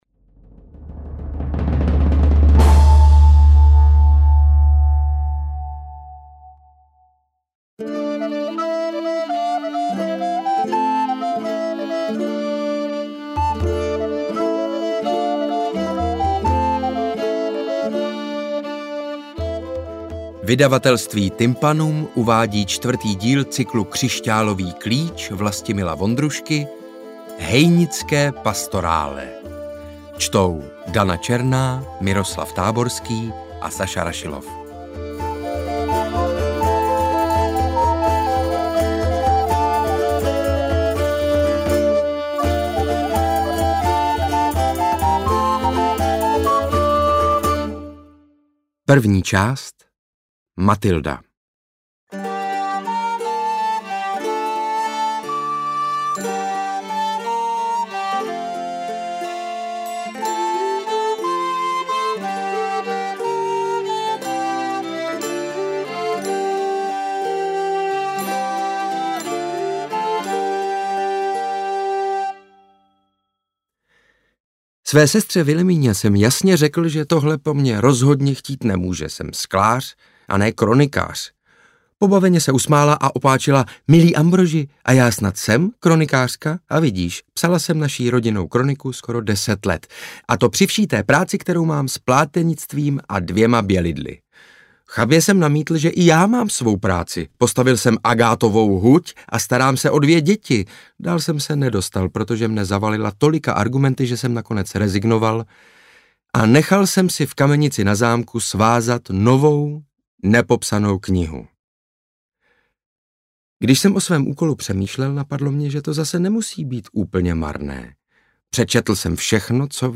Interpreti:  Dana Černá, Saša Rašilov, Miroslav Táborský